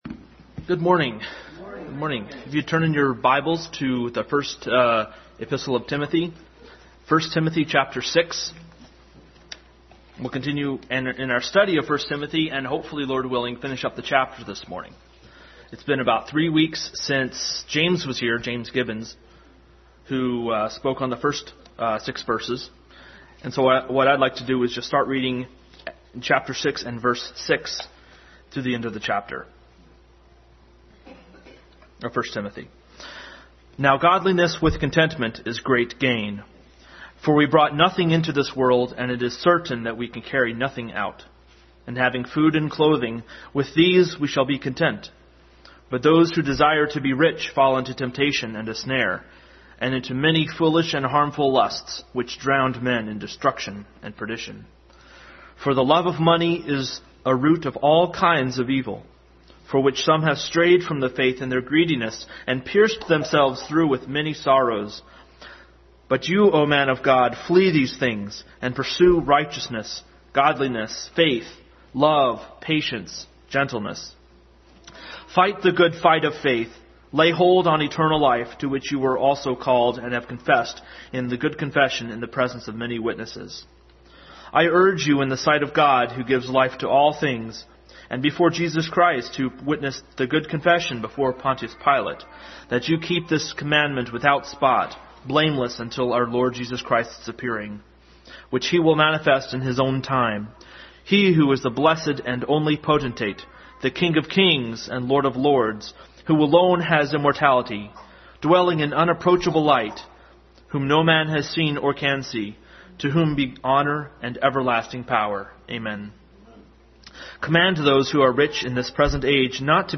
1 Samuel 9:6 Service Type: Sunday School Adult Sunday School continued study in 1 Timothy.